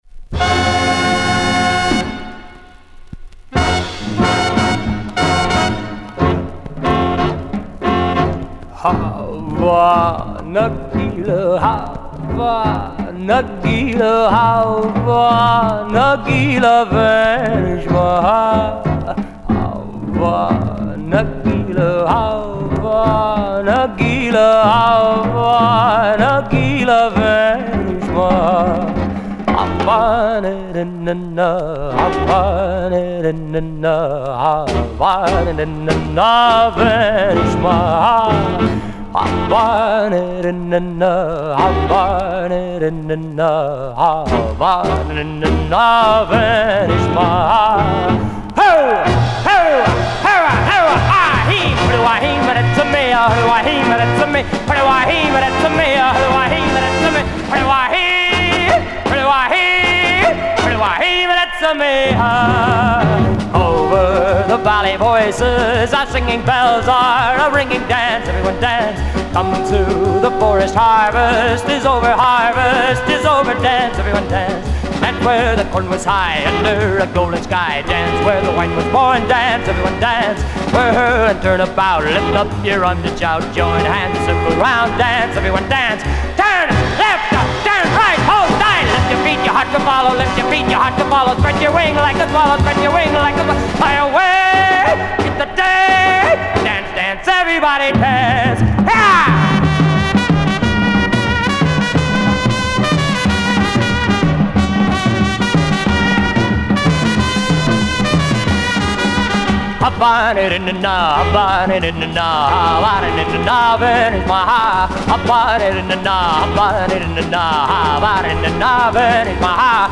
И несколько известных песен в исполнении Дина Рида.